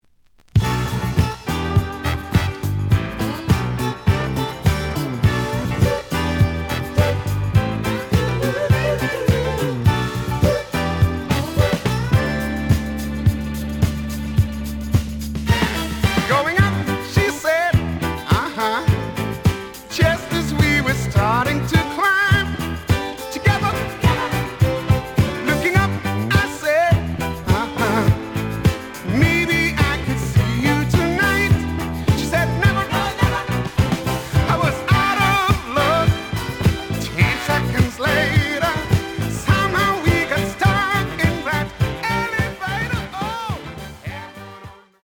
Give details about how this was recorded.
The audio sample is recorded from the actual item. Slight edge warp. But doesn't affect playing. Plays good.)